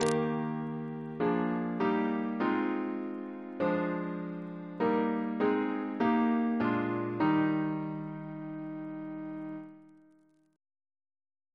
Single chant in E♭ Composer: Alexander Reinagle (1799-1877) Reference psalters: OCB: 216